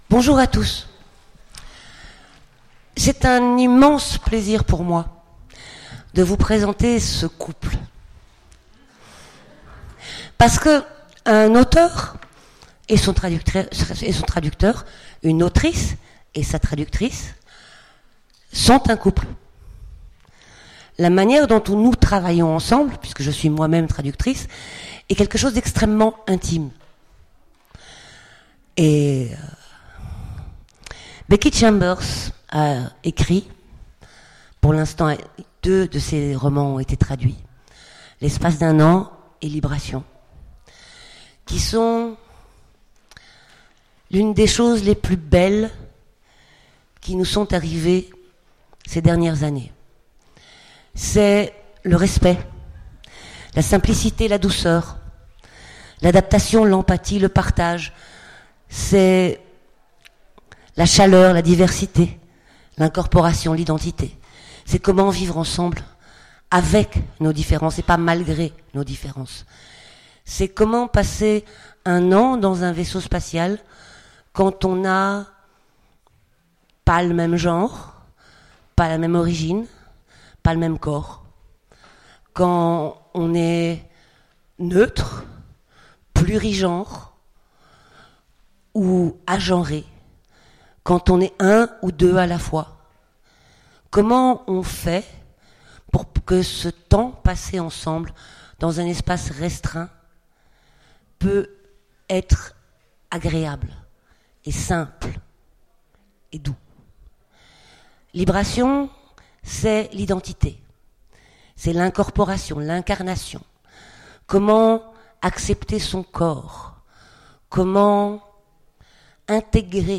Mots-clés Rencontre avec un auteur Conférence Partager cet article